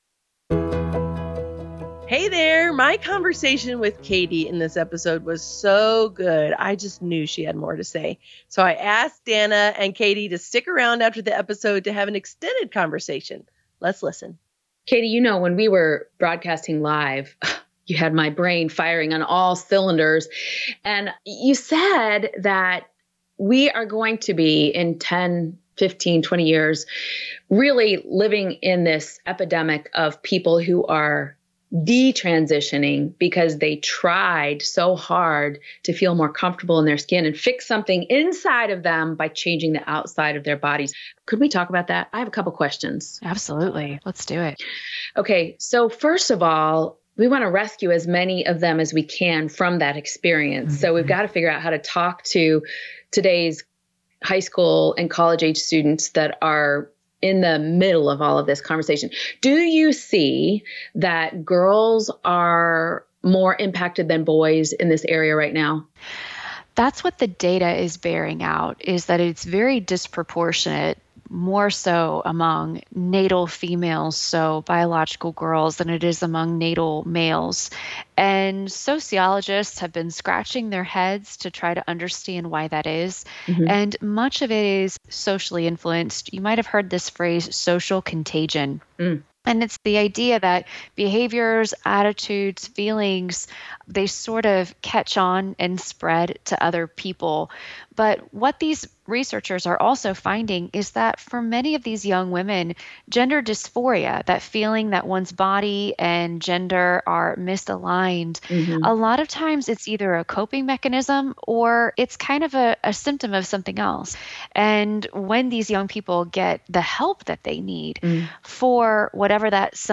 After the live program ended, the Grounded crew kept the cameras rolling!